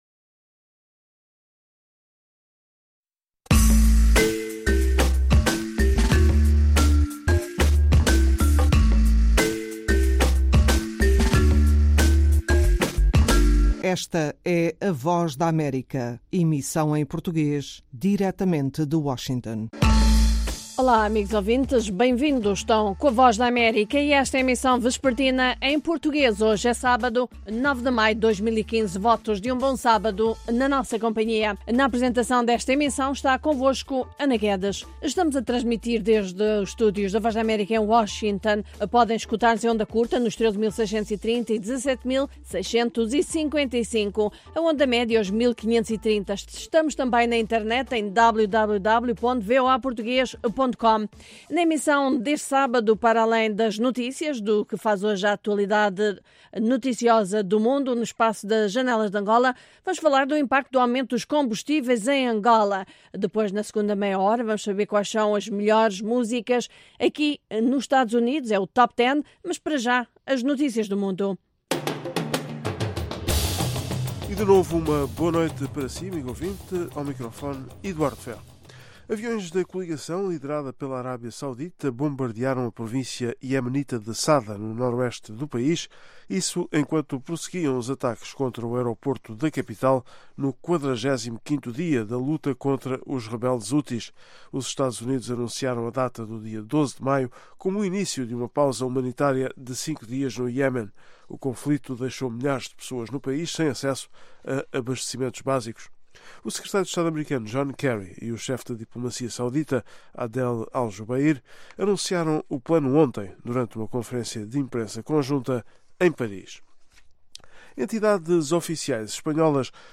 Meia-hora duas vezes por Aos sábados, ouça uma mesa redonda sobre um tema dominante da política angolana, música americana e as notícias do dia.